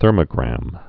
(thûrmə-grăm)